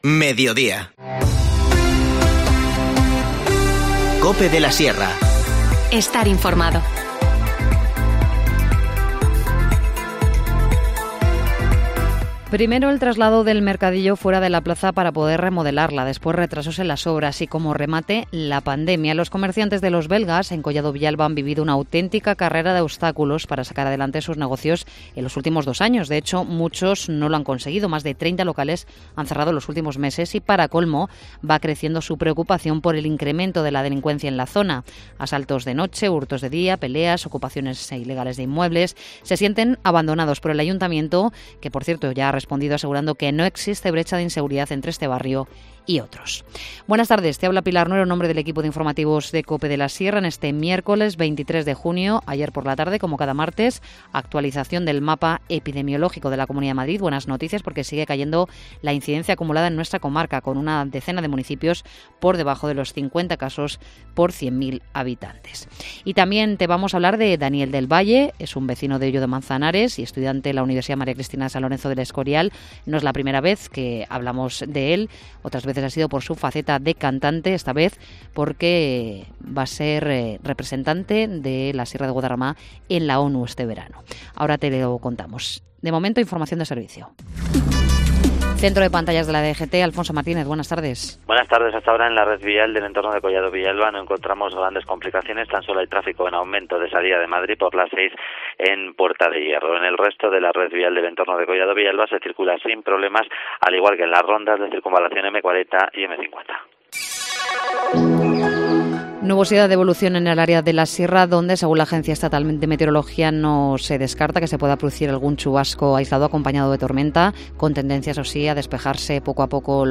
Informativo Mediodía 23 junio